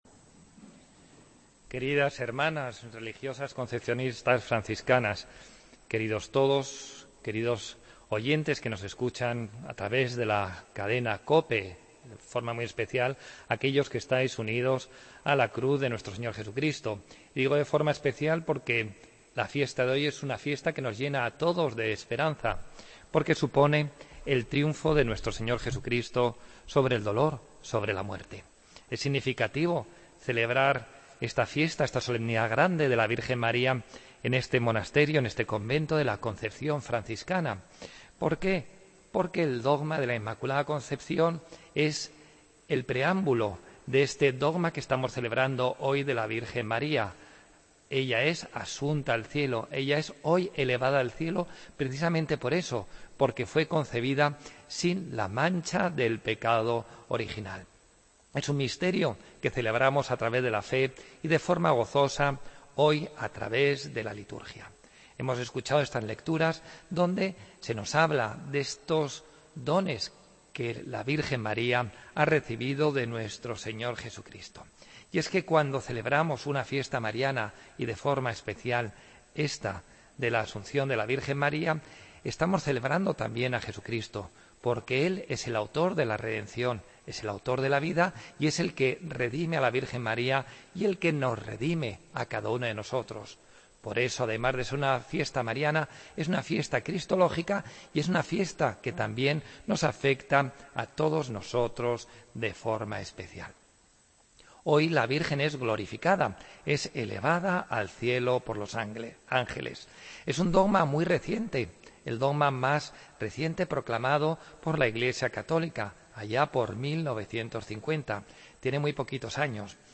Homilía del lunes, 15 de agosto de 2016